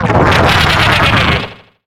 Cri d'Argouste dans Pokémon Soleil et Lune.